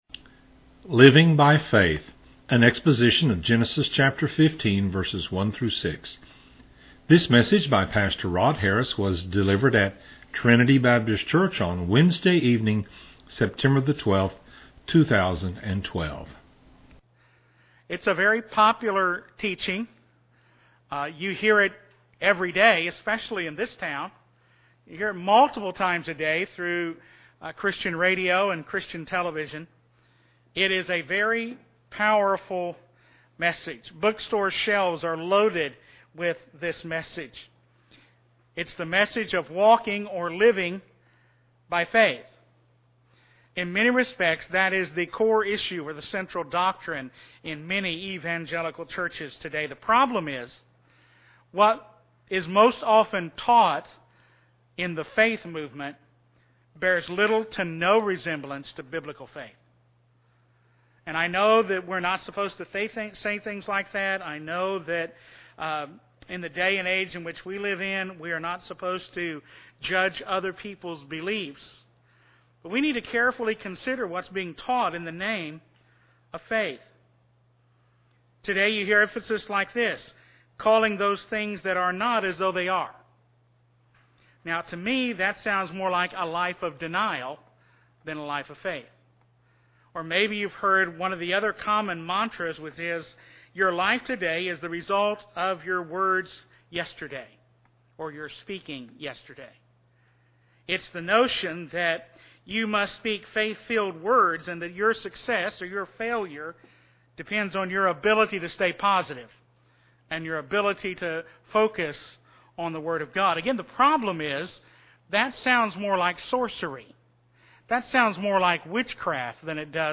An exposition of Genesis 15:1-6.